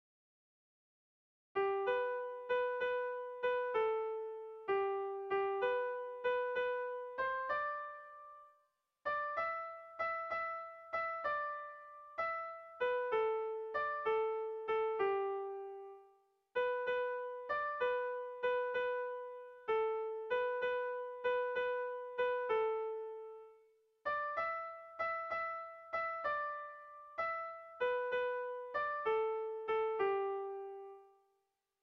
Erromantzea
Sara < Lapurdi Garaia < Lapurdi < Euskal Herria
Zortziko txikia (hg) / Lau puntuko txikia (ip)
ABDB